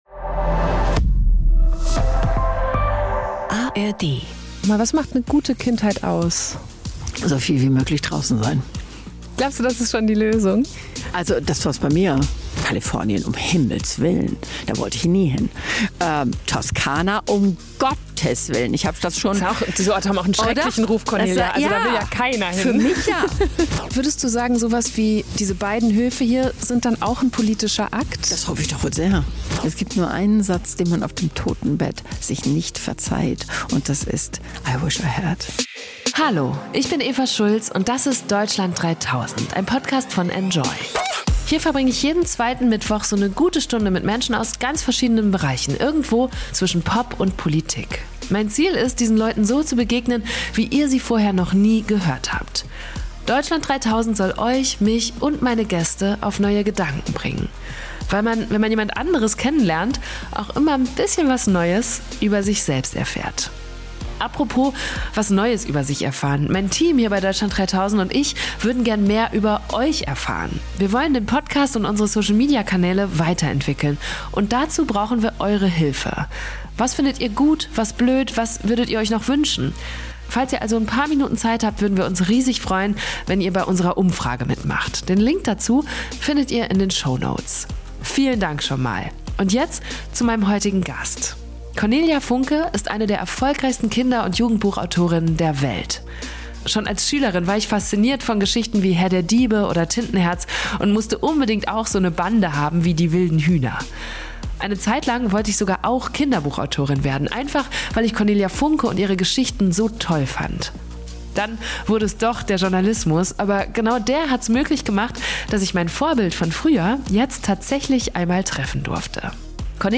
Dort saßen wir für dieses Interview in der toskanischen Hitze, um uns herum wuselten ihre beiden Hunde und die aktuellen Stipendiatinnen. Wir haben darüber gesprochen, was für einen Einfluss solche Orte - oder generell, wo man lebt - auf den eigenen Charakter, auf die komplette Biografie haben können. Wie man erkennt, dass es Zeit ist, den Ort zu wechseln - und wie hoch der Preis dafür ist.